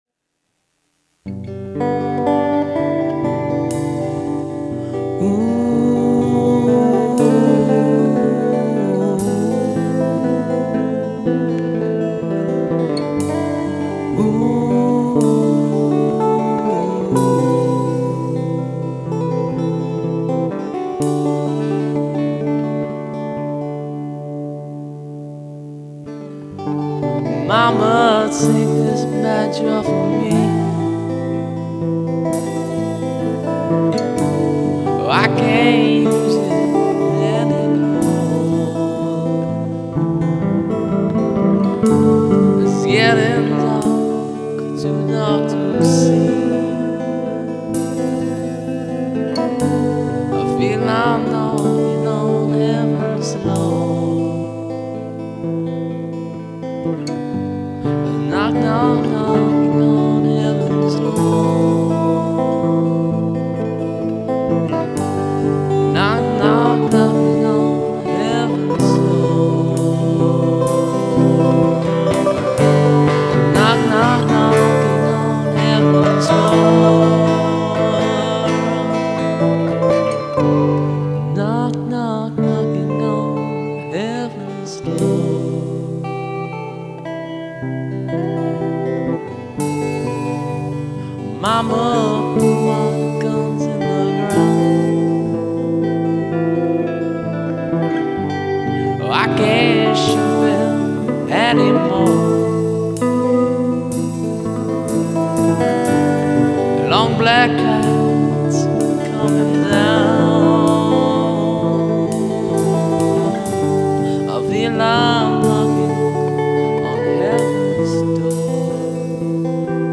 Classic